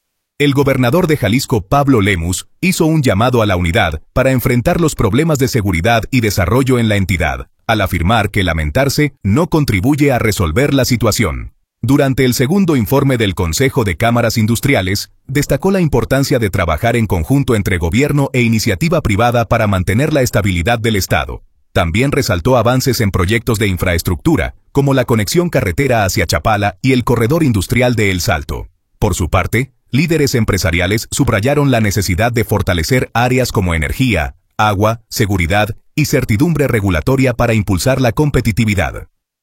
El gobernador de Jalisco, Pablo Lemus, hizo un llamado a la unidad para enfrentar los problemas de seguridad y desarrollo en la entidad, al afirmar que lamentarse no contribuye a resolver la situación. Durante el segundo informe del Consejo de Cámaras Industriales, destacó la importancia de trabajar en conjunto entre gobierno e iniciativa privada para mantener la estabilidad del estado. También resaltó avances en proyectos de infraestructura, como la conexión carretera hacia Chapala y el corredor industrial de El Salto.